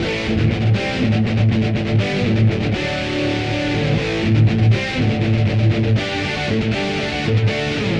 描述：简单的电吉他双音节奏。四组中的第二组。
Tag: 120 bpm Rock Loops Guitar Electric Loops 1.35 MB wav Key : Unknown